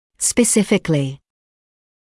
[spə’sɪfɪklɪ][спэ’сификли]специально; а именно; определенно, специфически